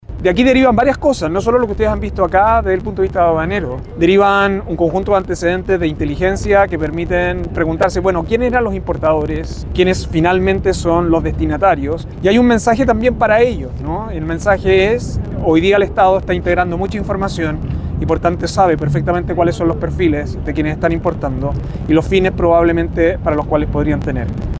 En el marco del operativo conjunto realizado esta semana en la región de Valparaíso, el subsecretario Cordero dijo que hoy el Estado está integrando mucha información, por lo que es posible saber los perfiles de quiénes están importando y los fines que podrían tener.